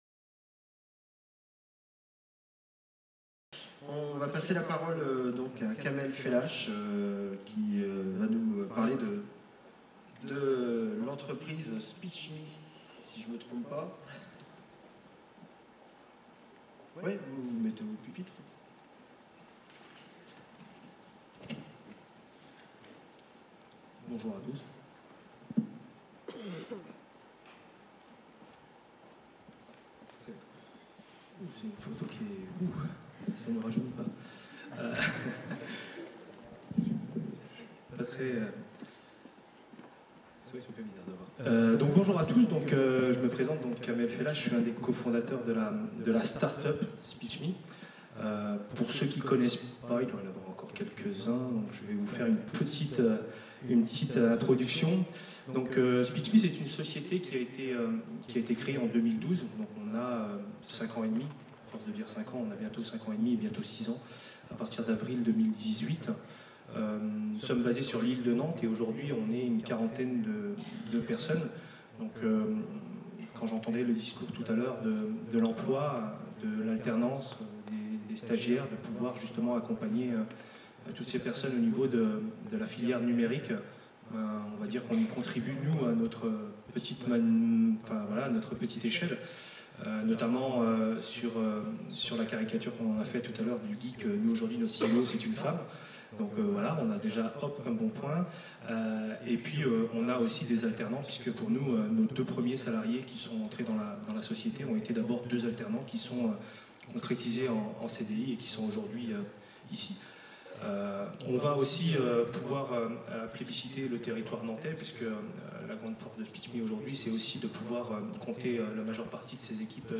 4ème rencontre : coorganisée le 5 décembre 2017, par la DGESIP et l'université de Nantes au Stéréolux (Ile de Nantes). Cycle de journées de rencontres sur les nouveaux modèles pour la F.T.L.V. 1er modèle : les effets du numérique sur l'organisation du travail, les réponses de l'enseignement supérieur.